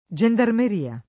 Jand#rme]: avanti, signori giandarmi! [av#nti, Sin’n’1ri Jand#rmi!] (Collodi) — dal fr. gendarme [X3d#rm], la forma gian- secondo la pronunzia (ma solo nell’uso pop. dell’800) e la forma gen- secondo la grafia — stessa alternanza nel der. gendarmeria [